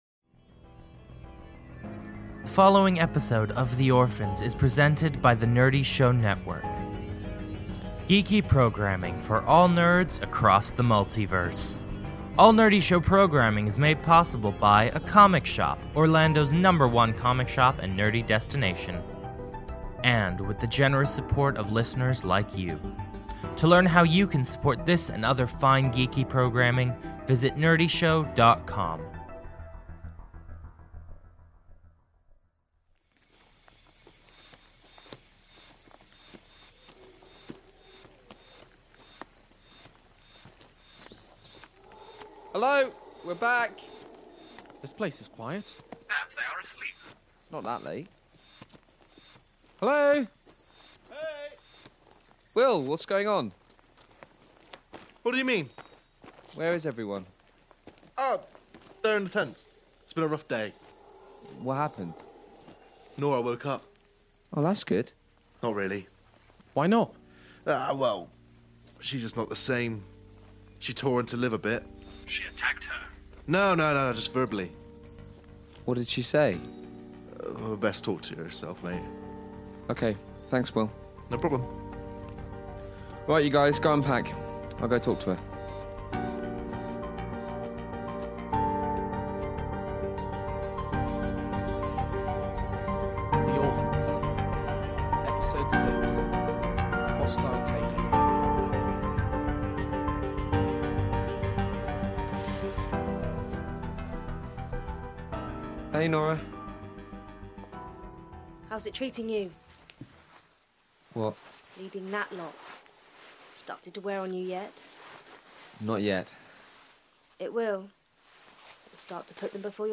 The Orphans is a cinematic audio drama chronicling the castaways of downed starship, The Venture - Stranded on a hostile planet, struggling to remember how they arrived and who they are.